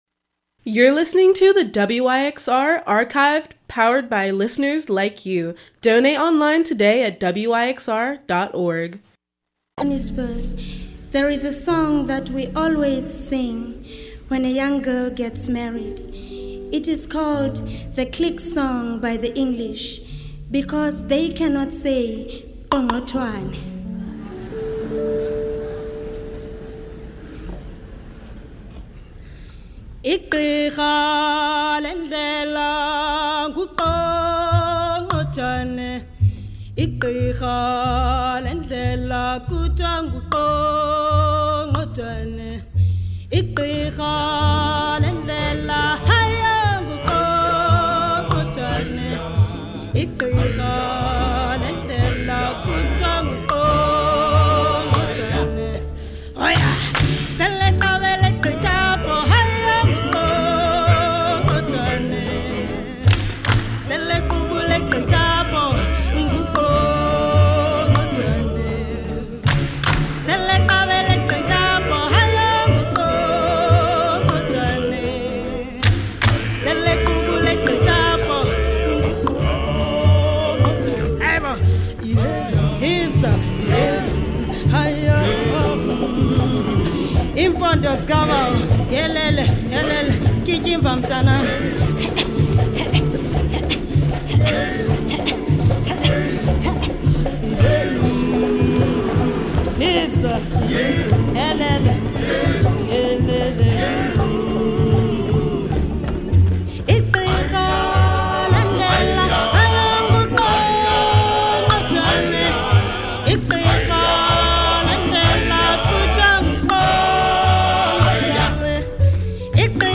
Rock World Soul